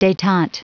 Prononciation du mot detente en anglais (fichier audio)
Prononciation du mot : detente